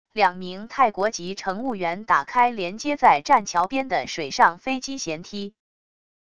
两名泰国籍乘务员打开连接在栈桥边的水上飞机舷梯wav音频